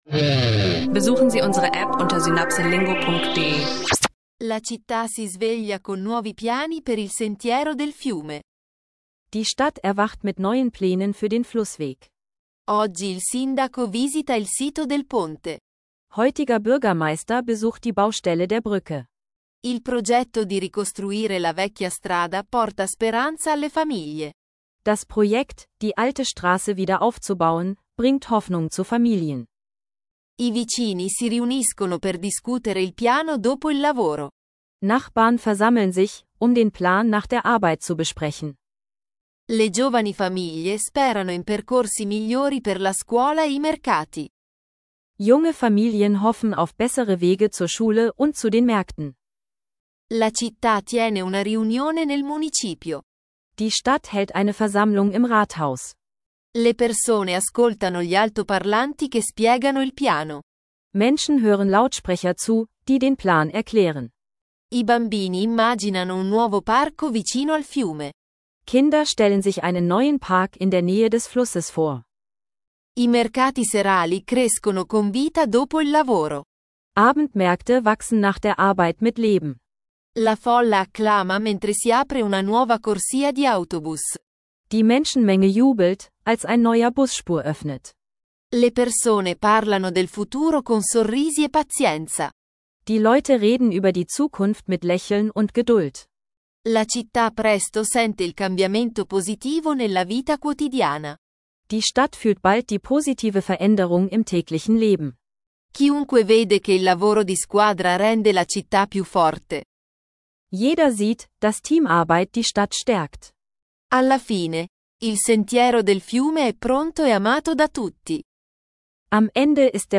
In dieser Folge von SynapseLingo dreht sich alles um Italienisch lernen online mit praktischen Dialogen zu öffentlichen Arbeiten, Stadtträumen und historischen Wendepunkten.